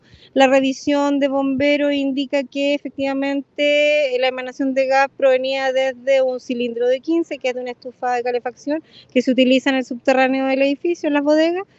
En conversación con La Radio, la administradora regional, Marila Barrientos, confirmó que la emanación vino de un cilindro de gas “de una estufa de calefacción que se utiliza en el subterráneo del edificio, en las bodegas”.